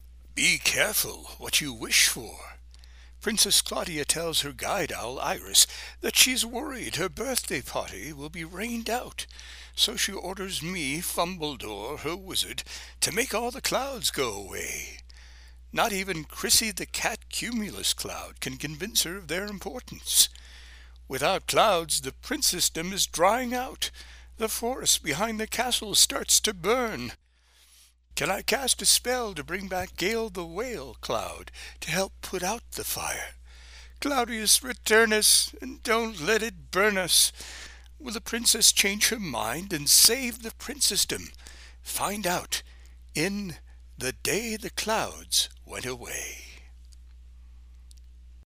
• Audiobook • 00 hrs 12 min